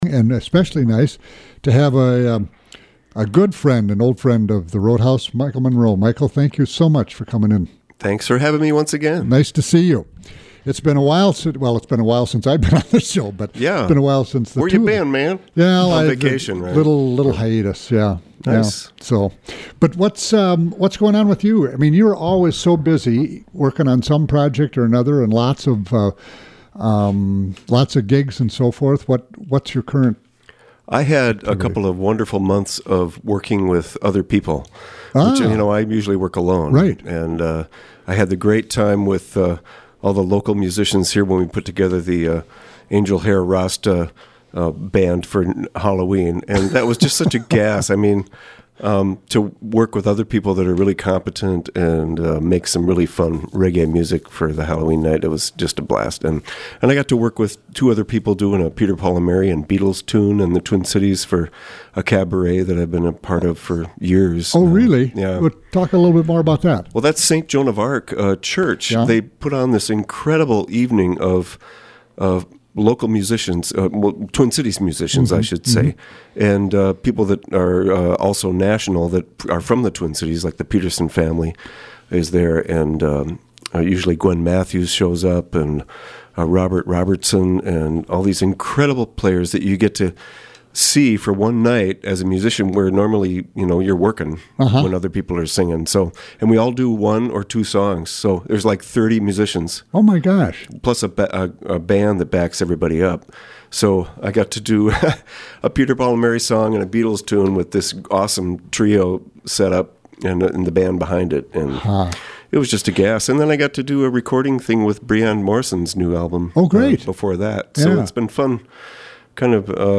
Program: Live Music Archive The Roadhouse